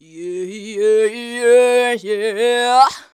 YEAHAYEAH.wav